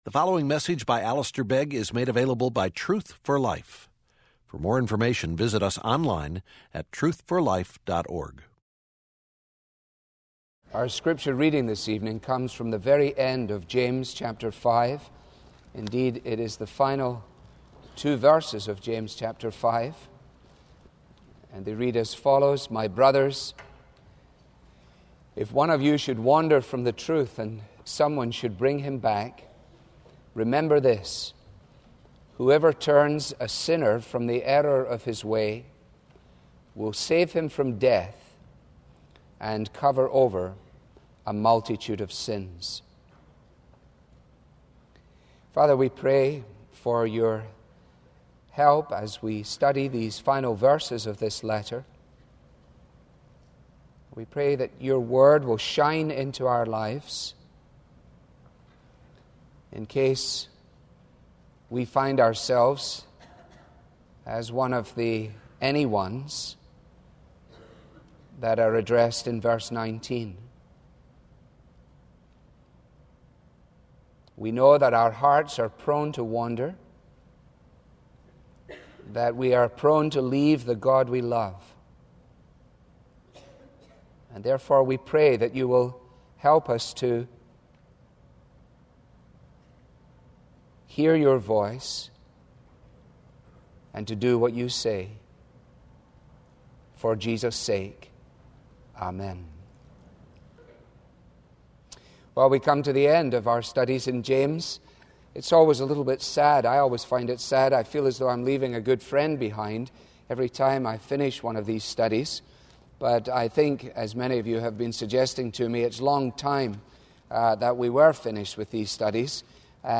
Sunday’s Sermon Series – Conversion